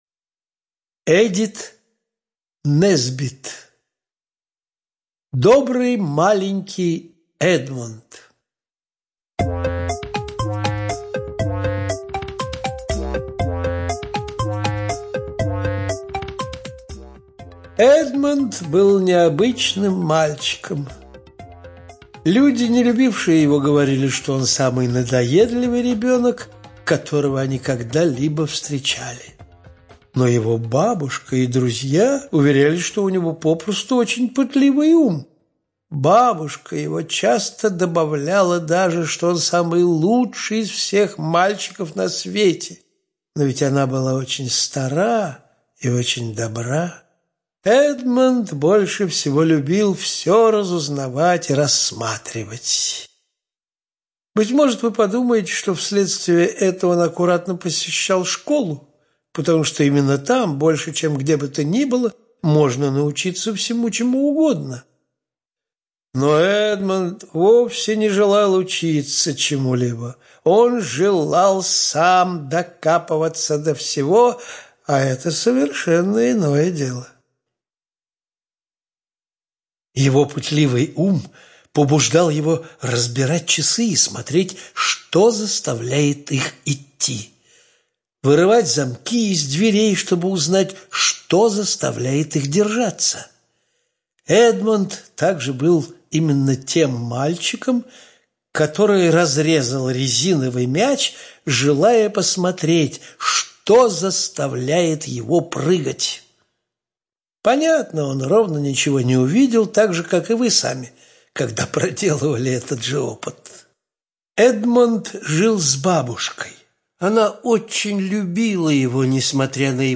Добрый маленький Эдмонд - аудиосказка Несбит - слушать онлайн